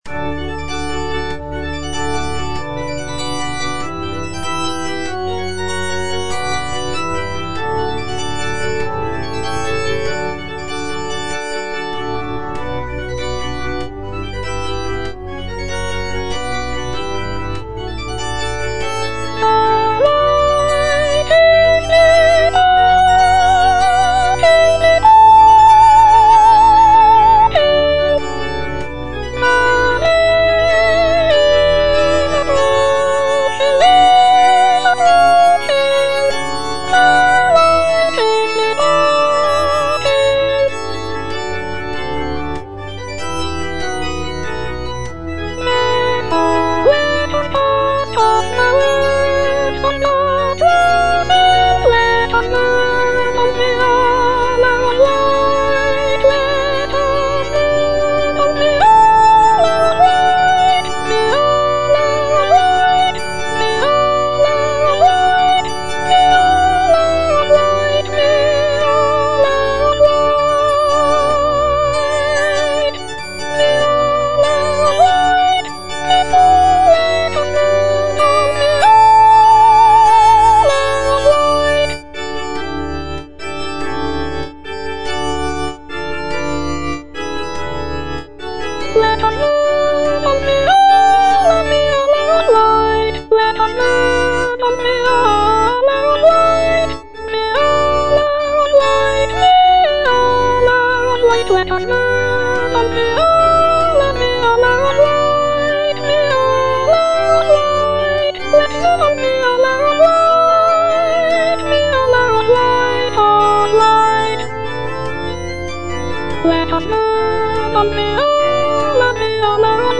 F. MENDELSSOHN - HYMN OF PRAISE (ENGLISH VERSION OF "LOBGESANG") The night is departing (soprano I) (Voice with metronome) Ads stop: